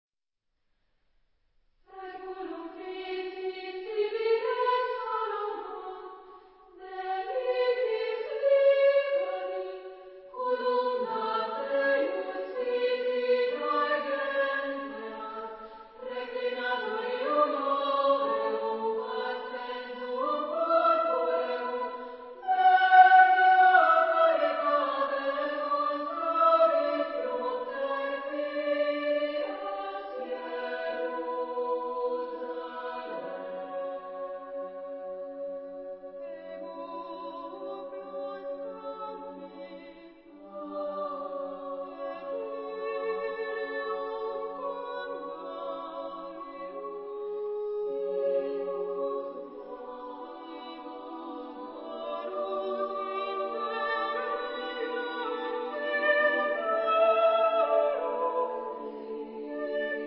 Genre-Stil-Form: geistlich ; Motette
Chorgattung: SSAA  (4 Frauenchor Stimmen )
Solisten: Soprano (1)  (1 Solist(en))
Tonart(en): tonal